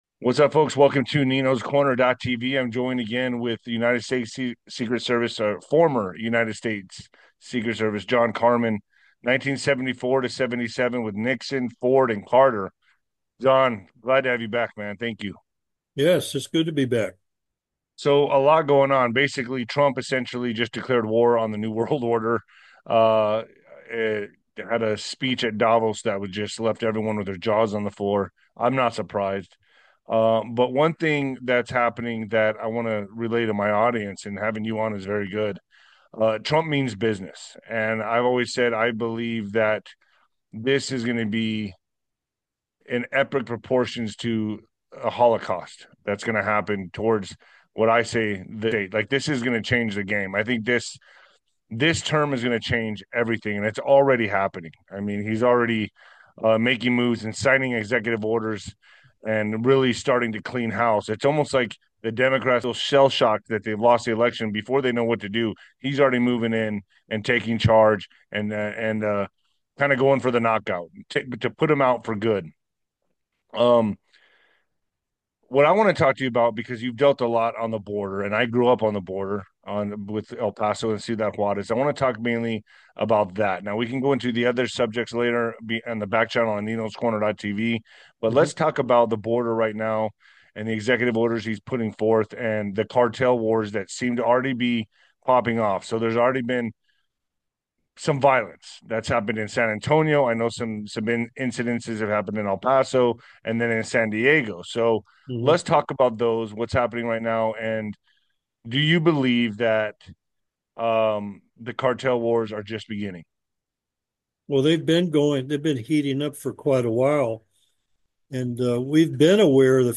They discuss the health benefits of olive oil and a recent attack on hikers by suspected cartel members. The conversation also covers travel warnings for Mexico and border security measures.